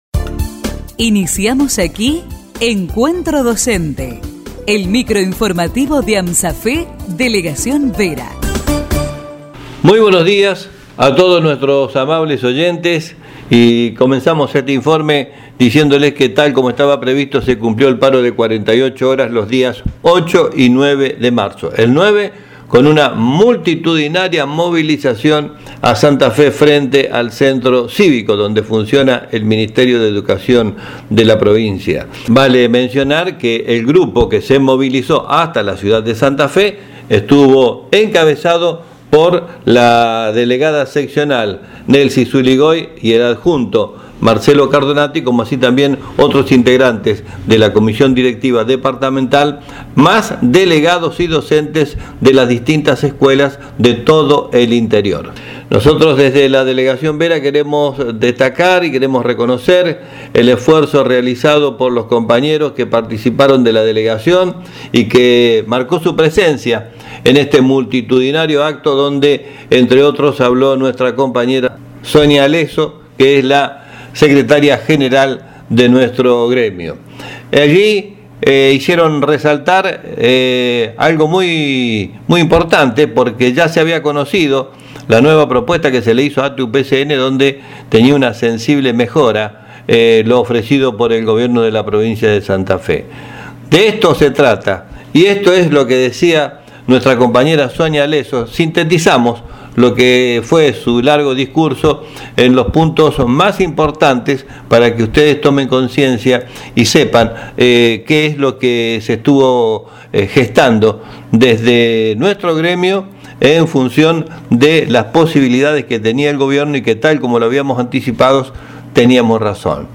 Micro informativo de AMSAFE Vera.